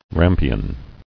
[ram·pi·on]